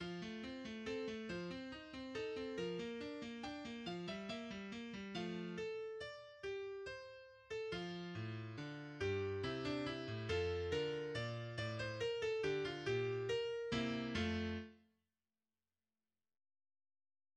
Тональность фа мажор
фортепиано
Первая часть написана в сонатной форме и состоит из экспозиции (такты 1-93), в которой происходит модуляция тем в ре минор и затем в до мажор, разработки (такты 94-132) и репризы (начинается с такта 133).